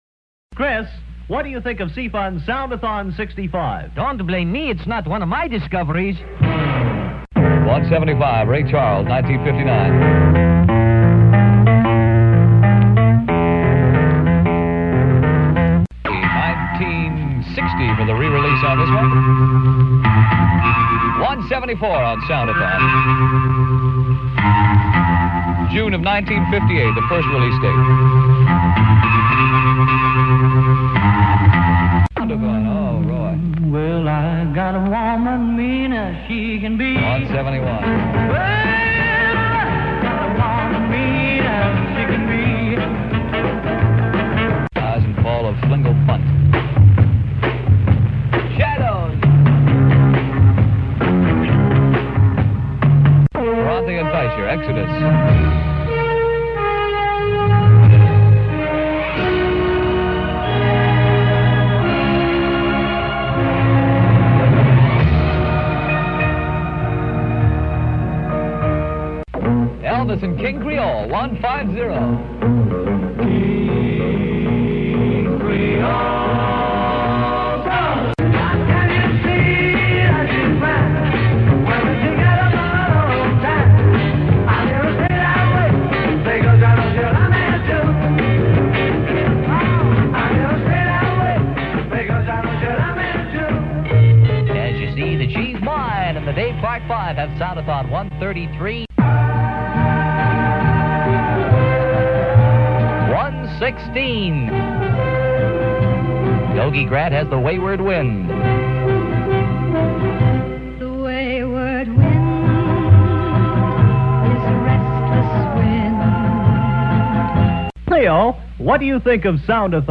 Montage!!
The C-FUN Good Guys introduce the songs in these audio clips recorded
directly from the radio during the actual event in late December 1964.
DJs heard in this Montage: